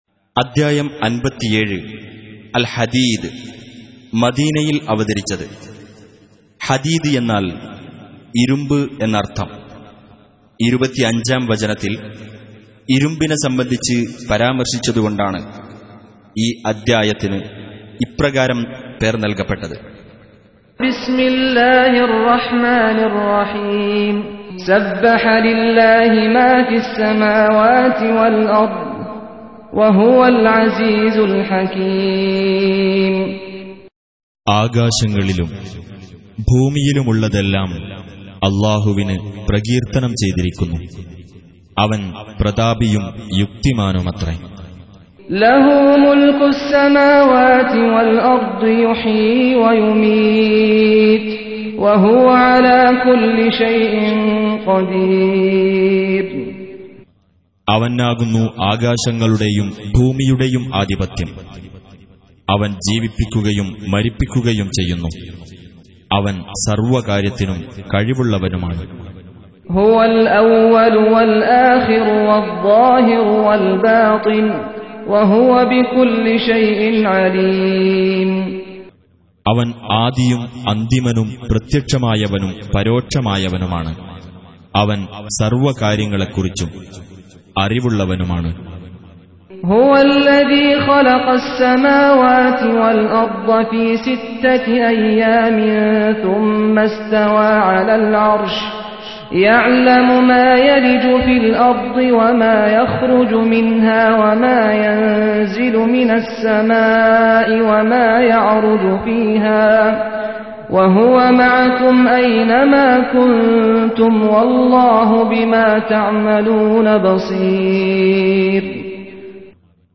Surah Repeating تكرار السورة Download Surah حمّل السورة Reciting Mutarjamah Translation Audio for 57. Surah Al-Had�d سورة الحديد N.B *Surah Includes Al-Basmalah Reciters Sequents تتابع التلاوات Reciters Repeats تكرار التلاوات